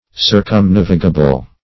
Search Result for " circumnavigable" : The Collaborative International Dictionary of English v.0.48: Circumnavigable \Cir`cum*nav"i*ga*ble\, a. Capable of being sailed round.